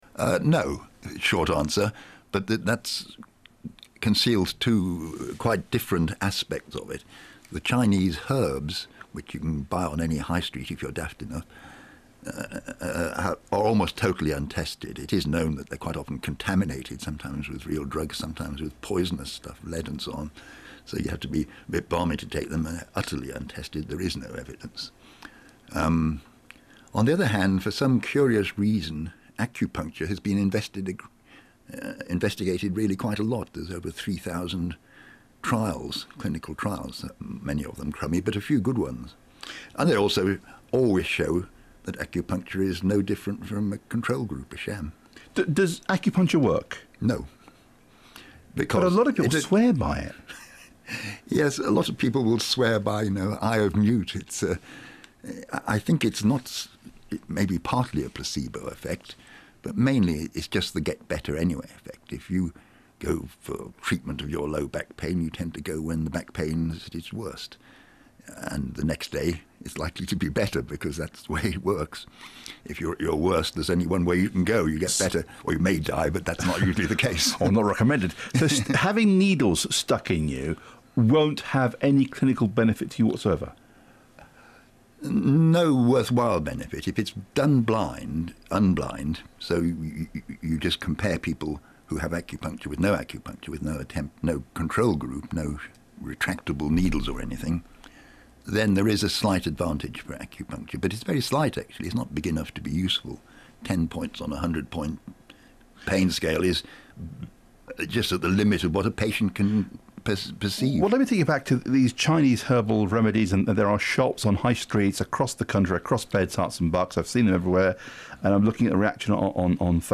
Then I got asked to do a pre-recorded interview for the local radio station, Radio Three Counties.